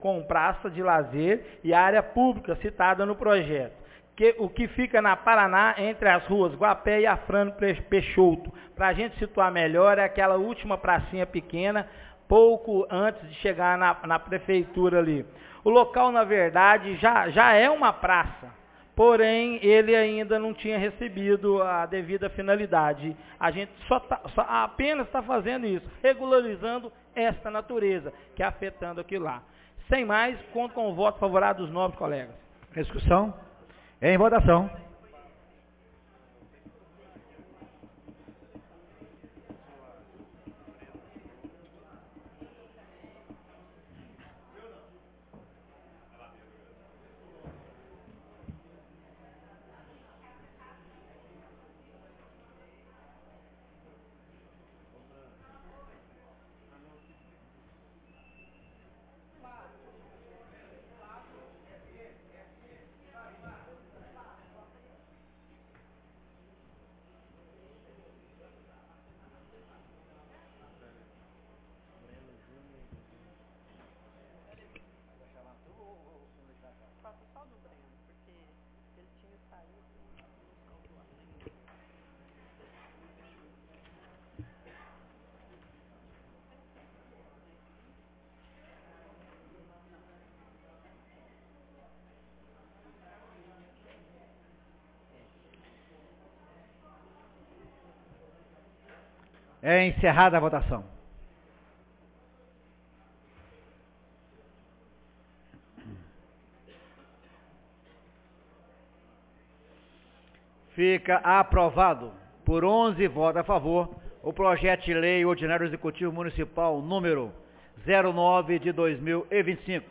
17ª Reunião Ordinaria 2 parti 03 de abril de 2025